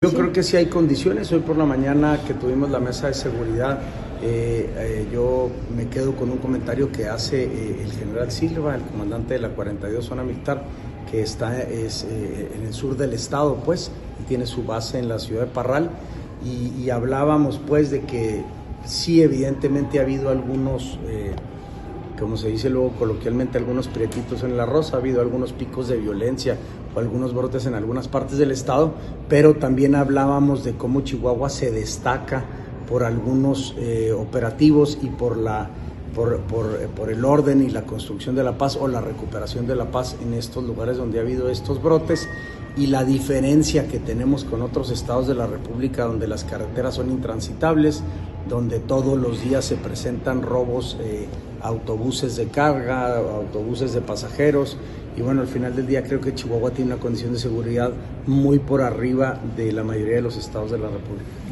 AUDIO: SANTIAGO DE LA PEÑA GRAJEDA, SECRETARIO GENERAL DE GOBIERNO (SGG)